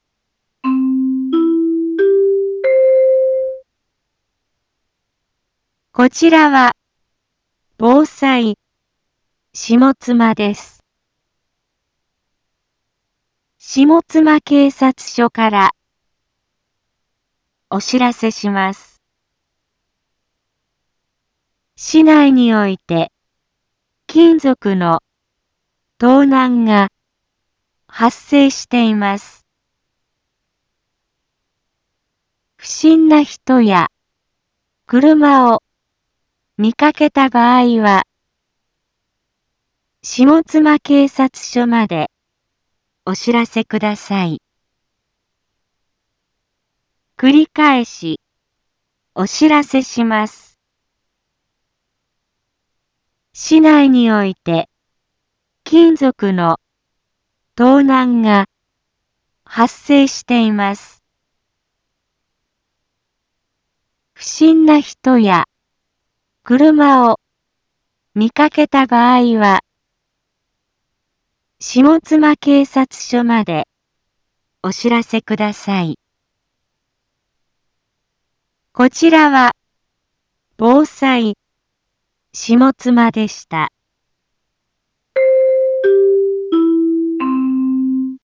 一般放送情報
Back Home 一般放送情報 音声放送 再生 一般放送情報 登録日時：2022-02-04 12:31:32 タイトル：金属の盗難被害について インフォメーション：こちらは防災下妻です。